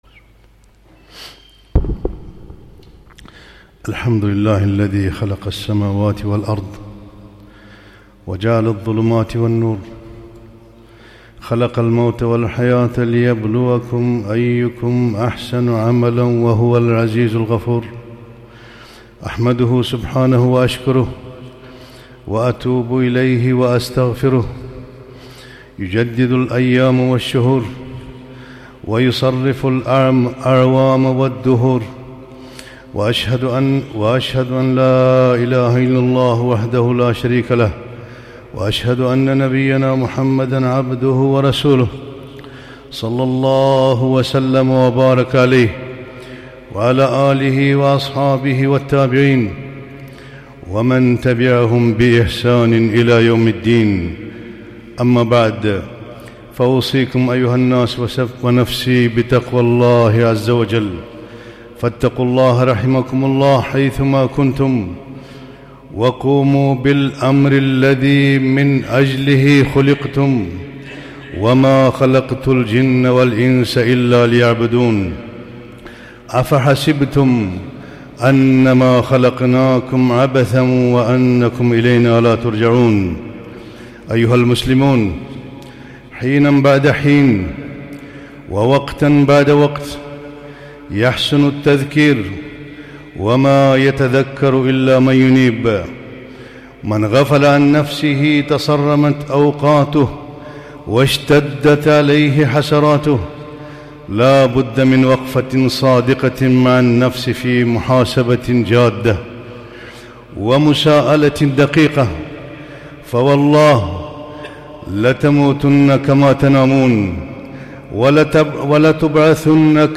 خطبة - حياة القلوب وأمراضها